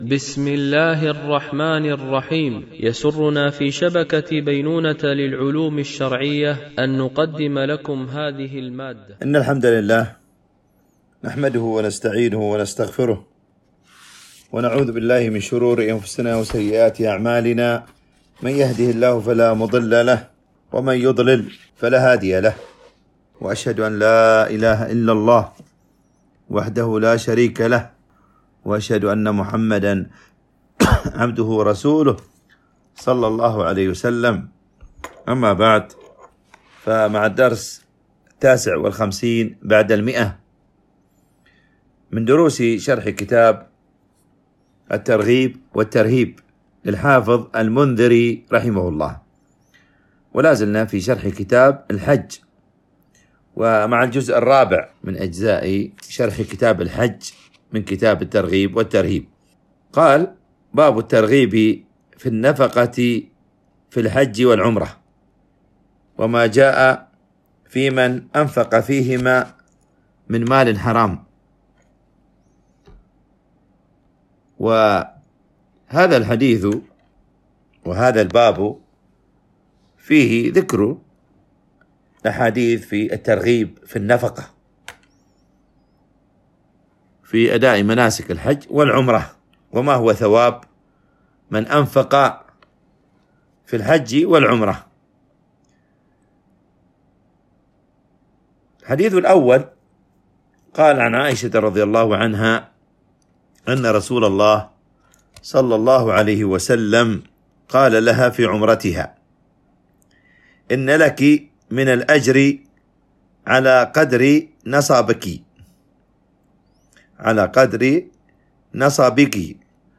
شرح كتاب الترغيب والترهيب - الدرس 159 ( كتاب الحج - الجزء الرابع - باب الترغيب في النفقة في الحج والعمرة... )